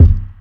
CDK - EI Kick2.wav